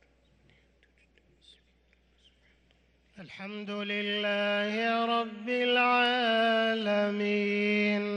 صلاة العشاء للقارئ عبدالرحمن السديس 1 جمادي الآخر 1444 هـ
تِلَاوَات الْحَرَمَيْن .